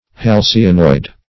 Search Result for " halcyonoid" : The Collaborative International Dictionary of English v.0.48: Halcyonoid \Hal"cy*o*noid\ (h[a^]l"s[i^]*[-o]*noid), a. & n. [Halcyon + -oid.]